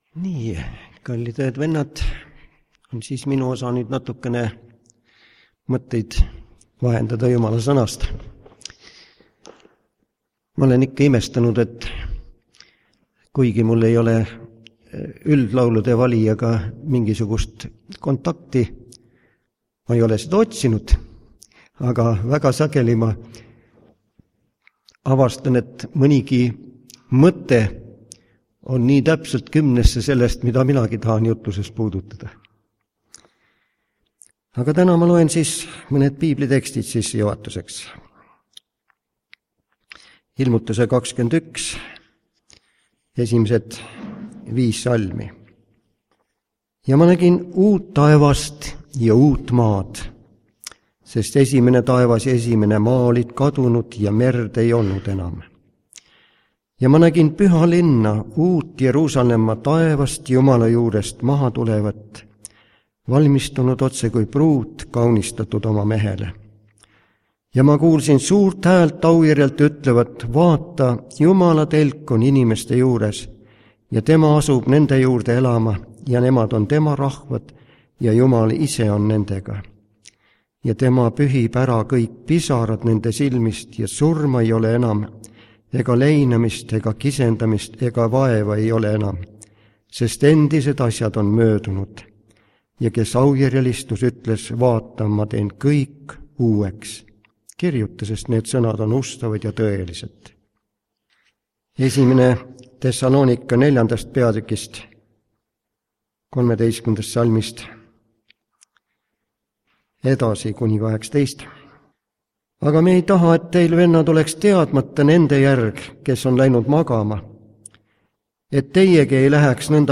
Jutlused